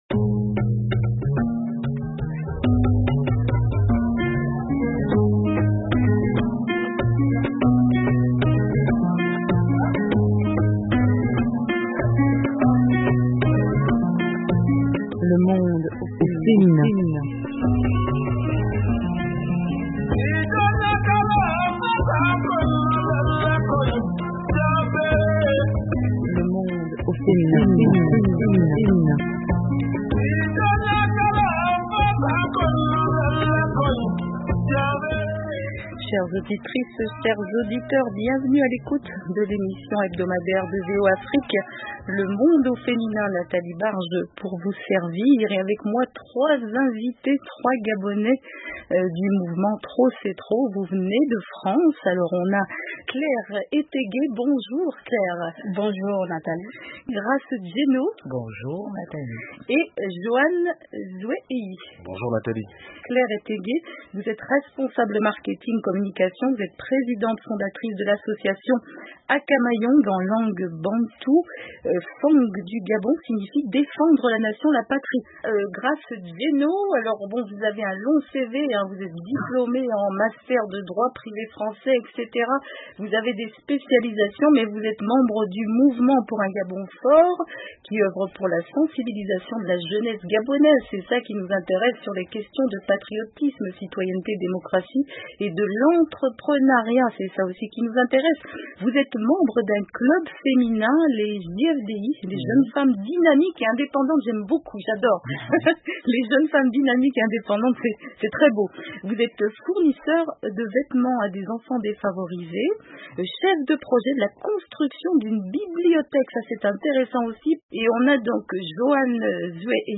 pour une discussion sur la jeunesse gabonaise.